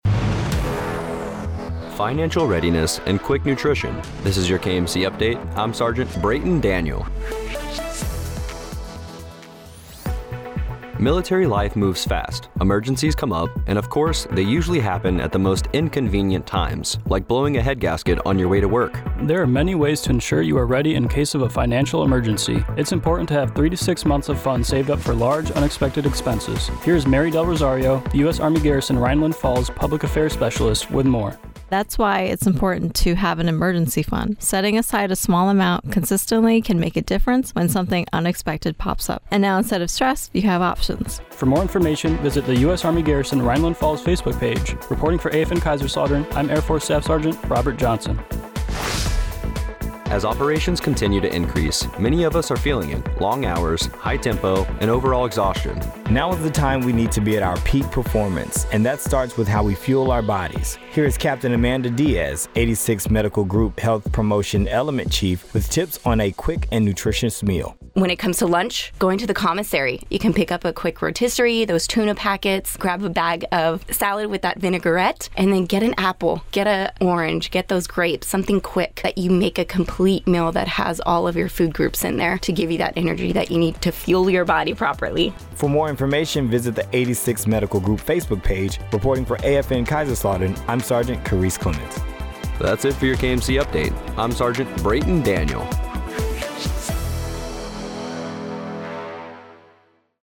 A Kaiserslautern Military Community Update on financial readiness